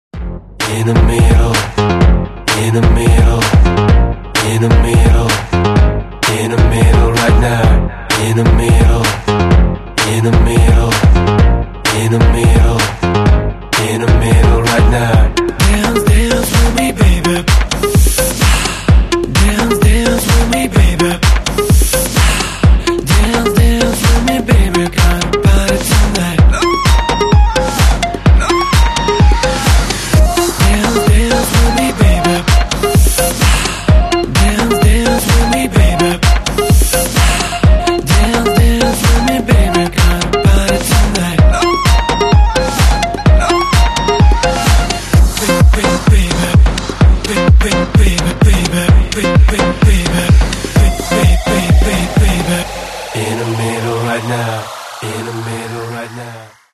Каталог -> Поп (Легкая) -> Клубная